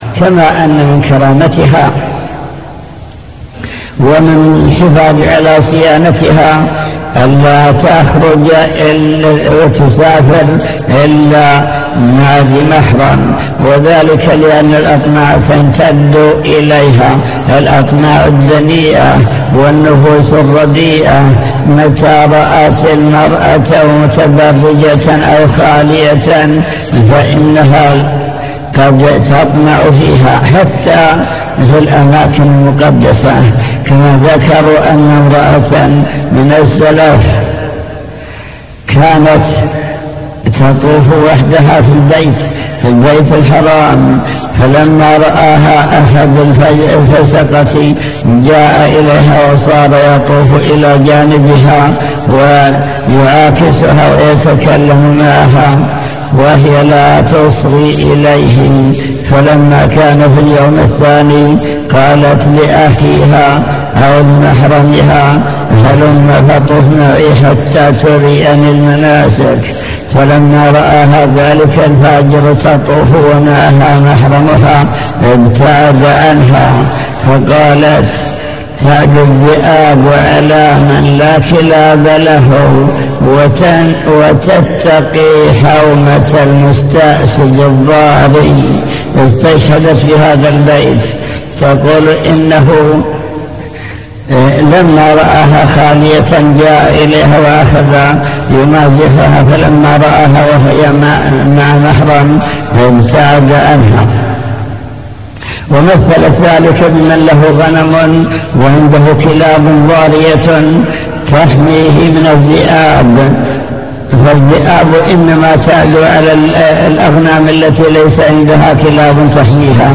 المكتبة الصوتية  تسجيلات - محاضرات ودروس  فتاوى عن المرأة